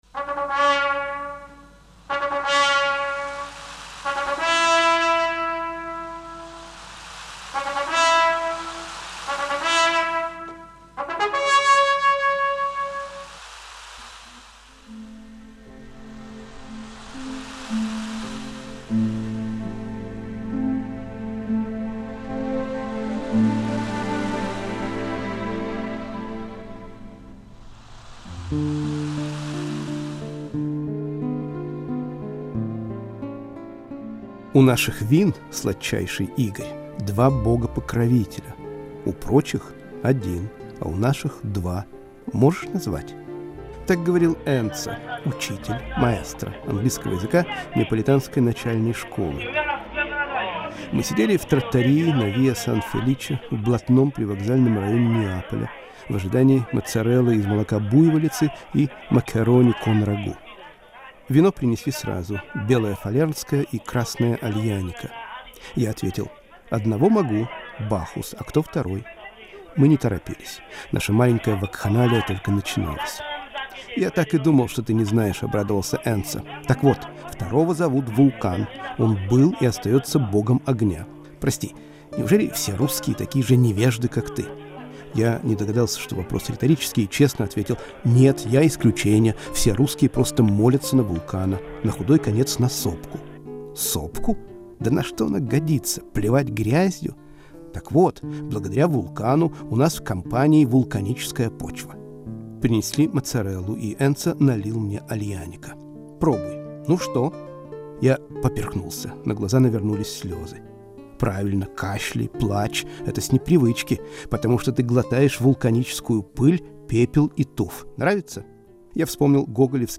Это просто эссе (радиоверсию можно послушать).